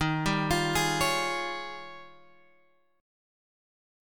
D# 11th